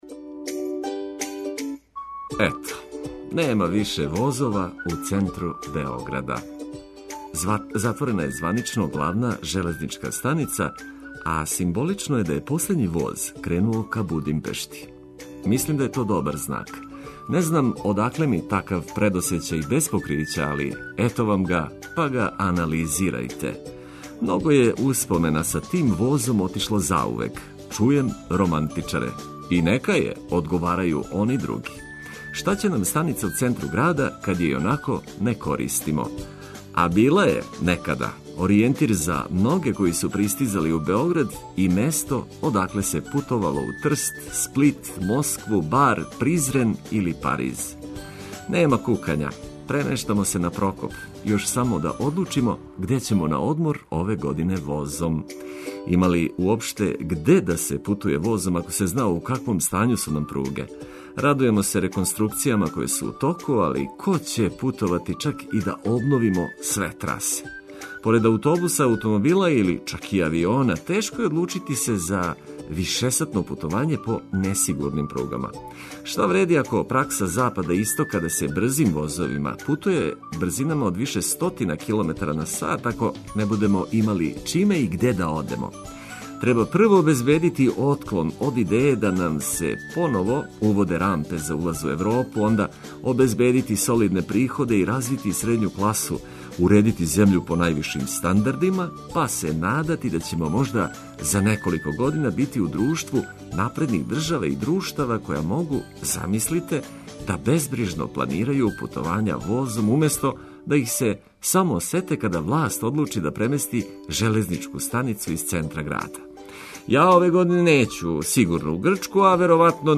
Почињемо радно, али уз музику и лепо расположење.